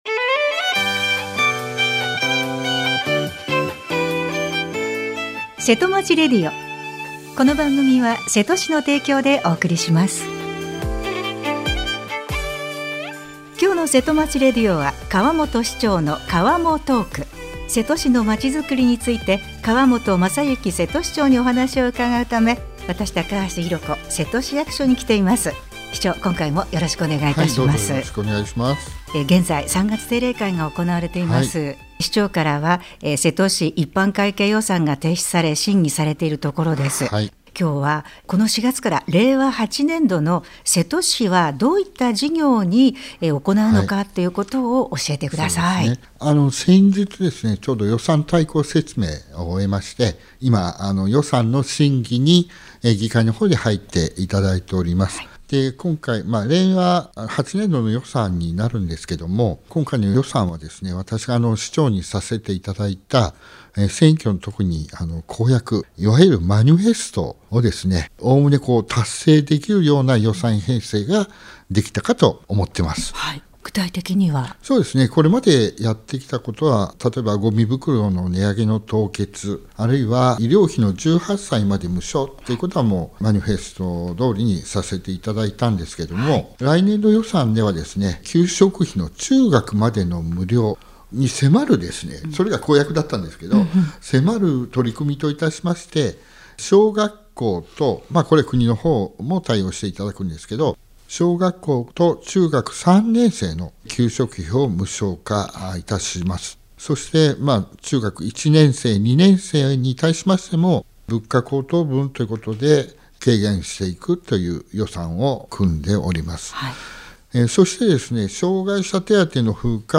川本雅之瀬戸市長にお話を伺いました。